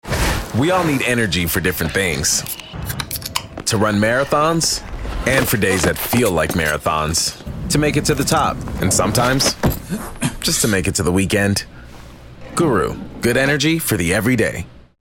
Commercial (Guru) - EN